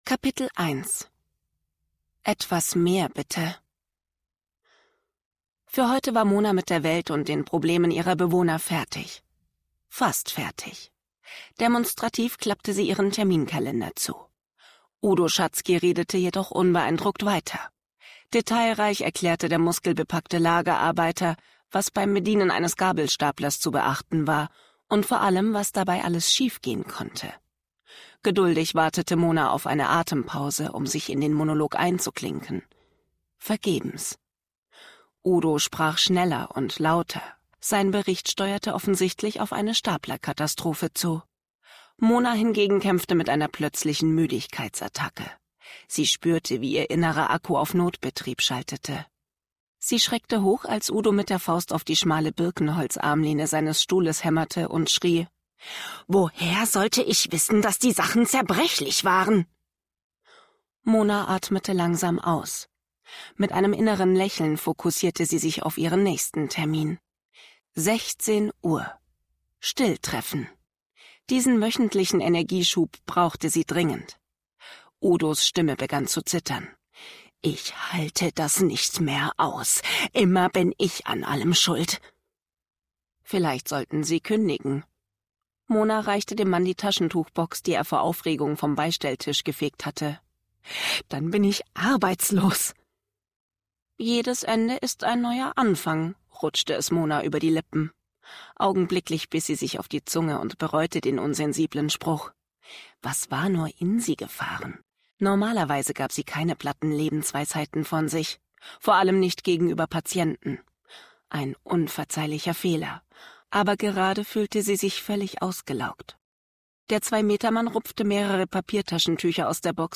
Hörbuch
2023 | 1. Ungekürzte Ausgabe